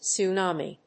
音節tsu・na・mi 発音記号・読み方
/tsʊnάːmi(米国英語), tsu:ˈnɑ:mi:(英国英語)/